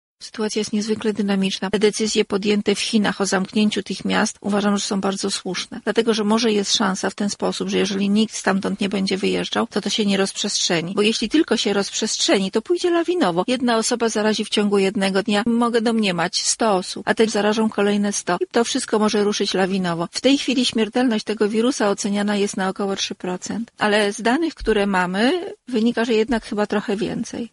Rozmowa z Irminą Nikiel